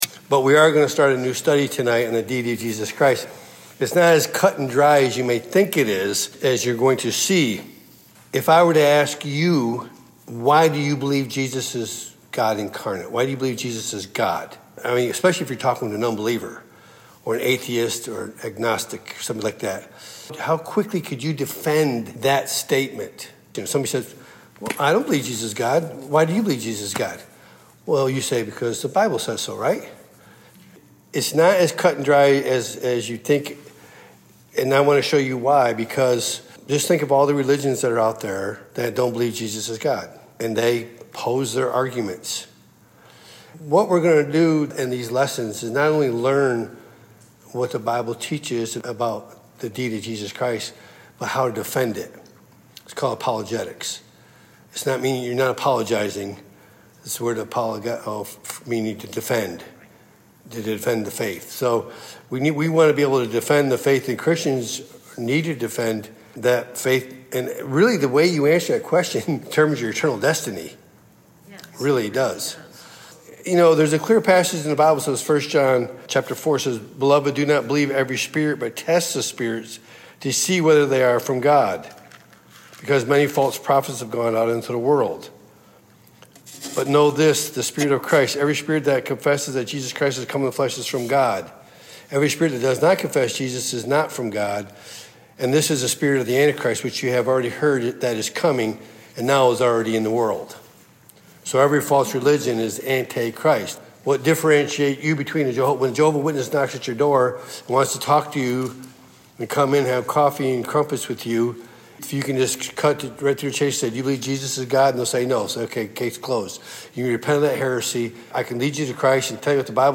Topic: Wednesday Pastoral Bible Study